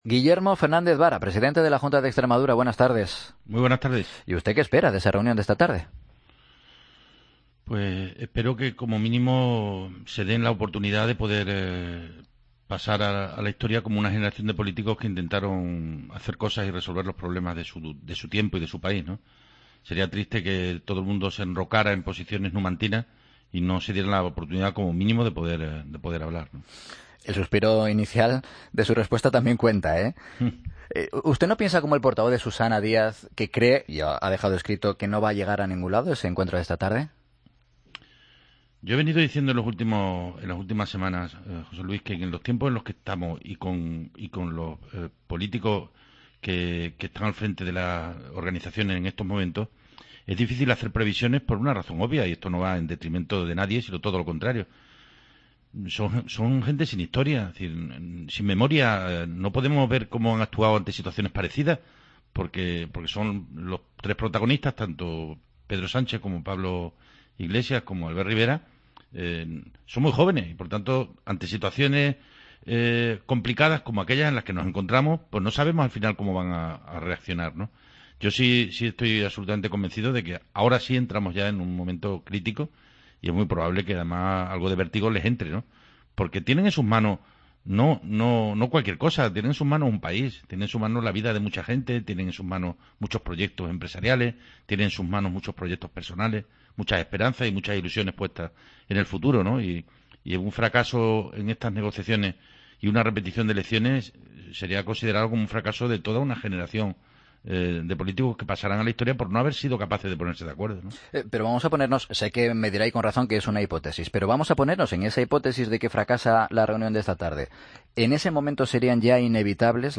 AUDIO: Escucha la entrevista a Guillermo Fernández Vara, presidente de Extremadura, en 'Mediodía COPE'